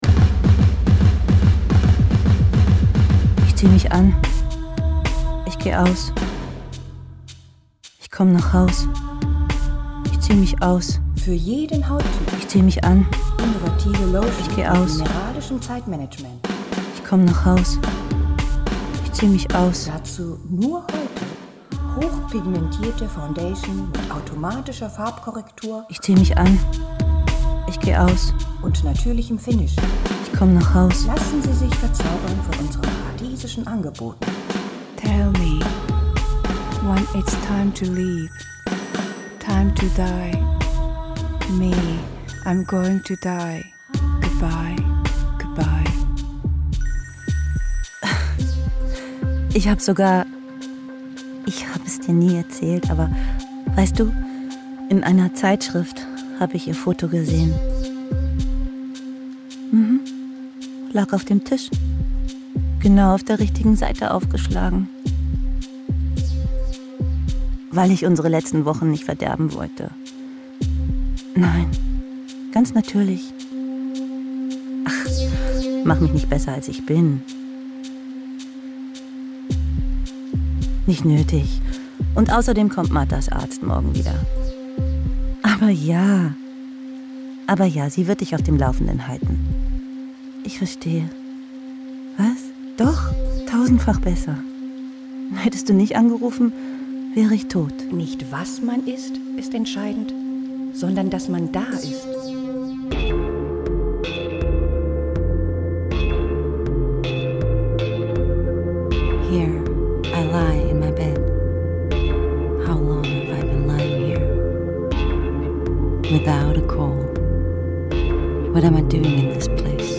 Aufnahmen und Mischung @ Deutschlandradio Kultur